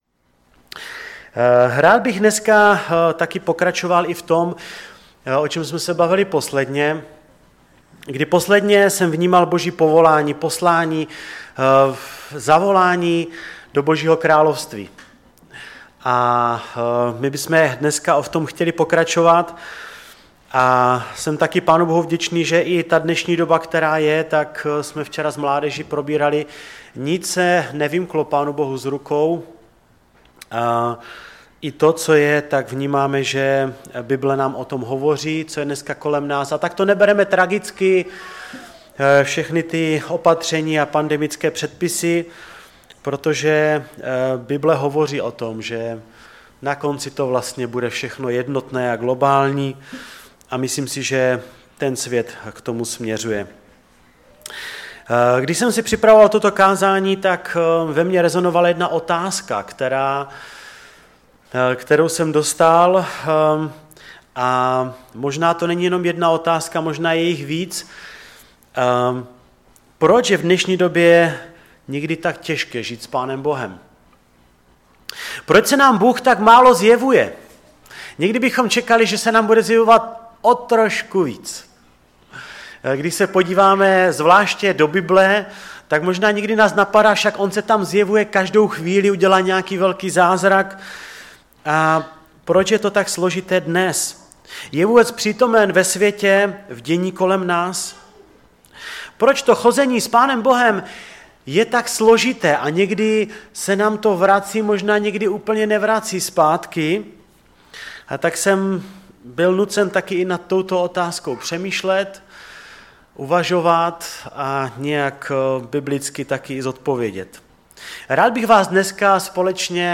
Kázání
ve sboře Ostrava-Radvanice.